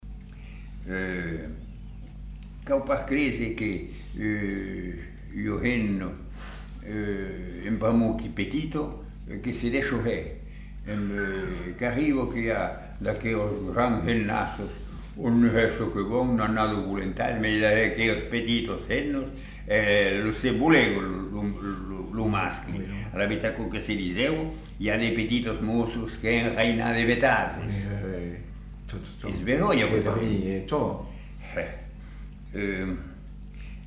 Lieu : Masseube
Effectif : 1
Type de voix : voix d'homme
Production du son : récité
Classification : proverbe-dicton